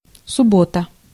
Ääntäminen
IPA: /sam.di/